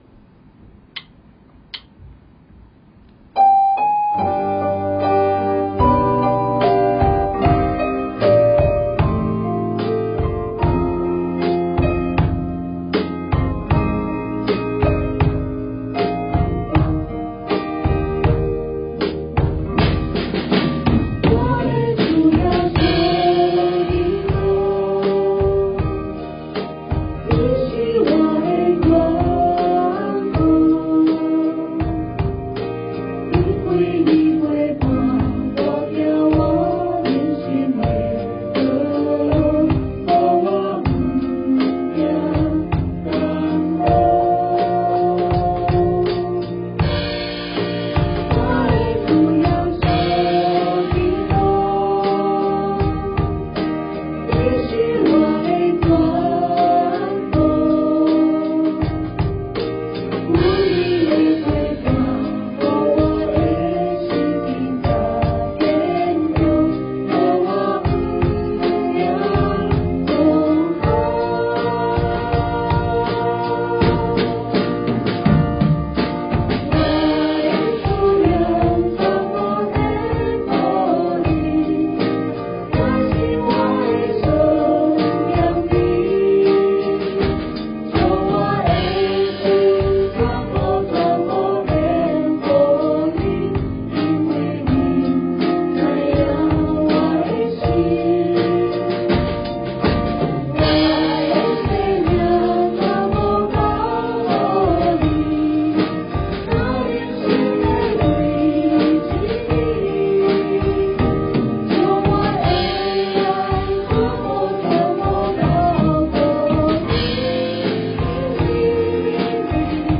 他们都不太会说台语，但短短几次练唱后，他们也渐渐唱出台语独特的味道，看来天主的恩宠无所不在，只要你愿意接受祂给你的安排。